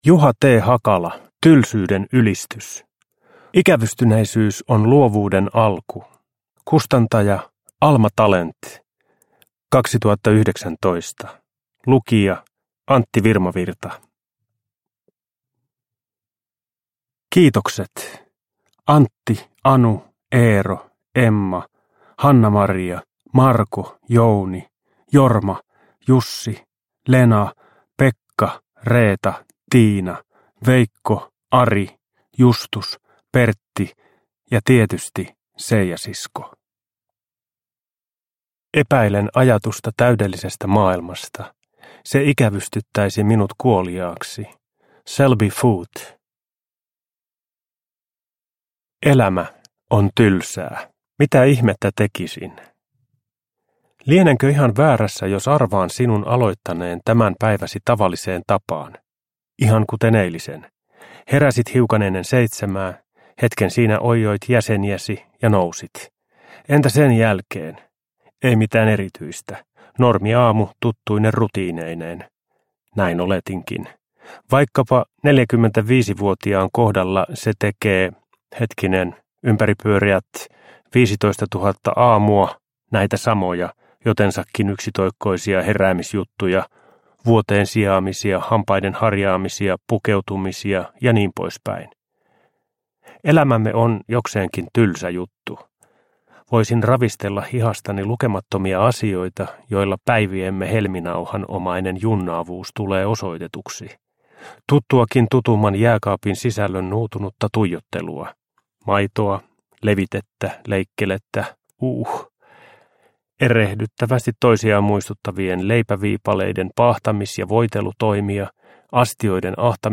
Uppläsare: Antti Virmavirta